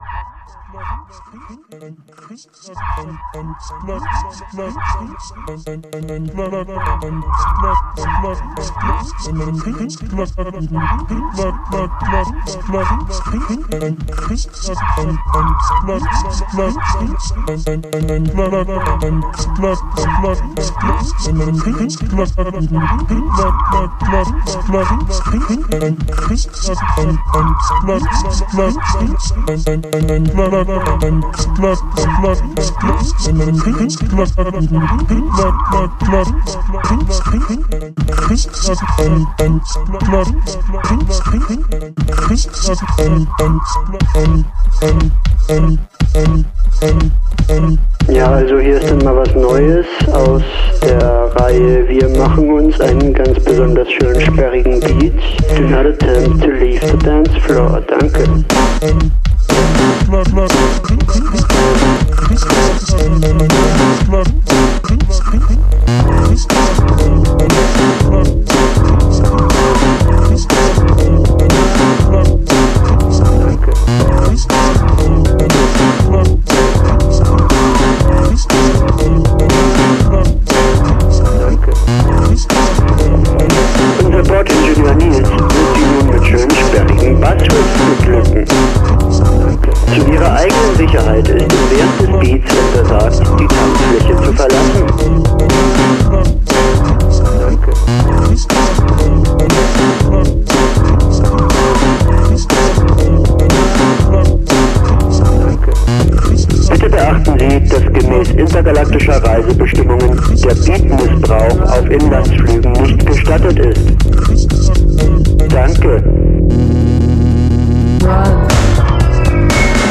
funky & electronic